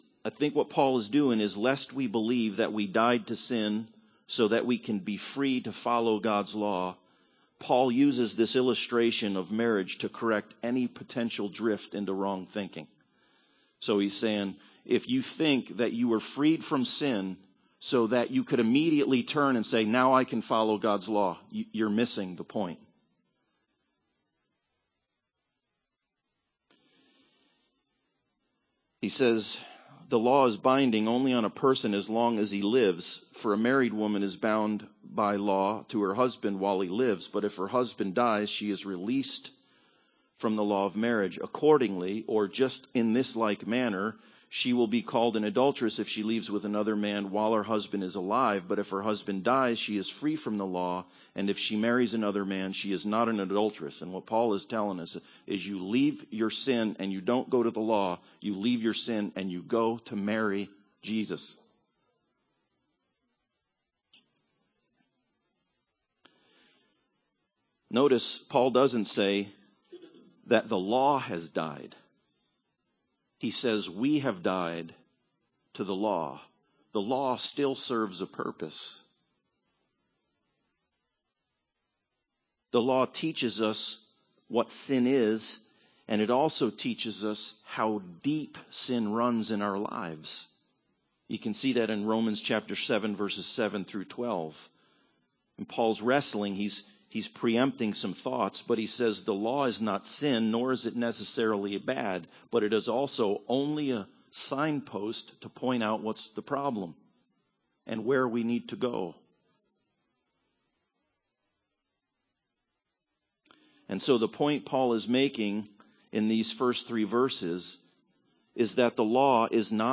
Passage: Romans 7 Service Type: Sunday Service